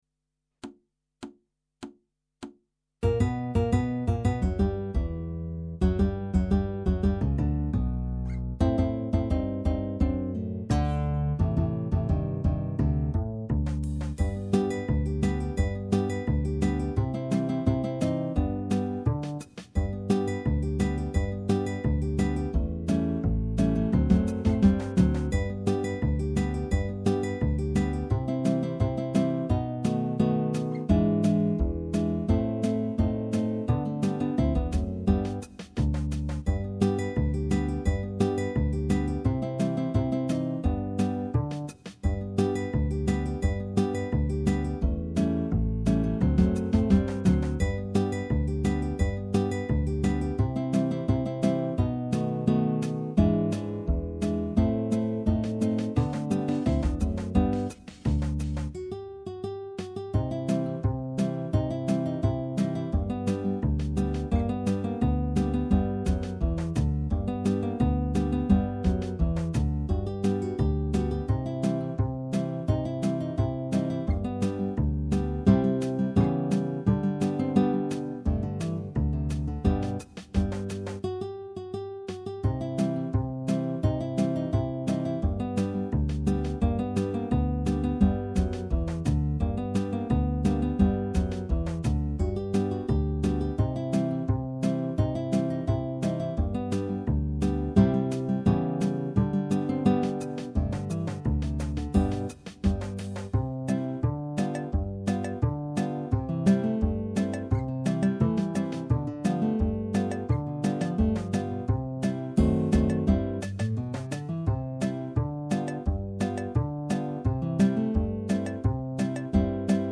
minus Guitar 1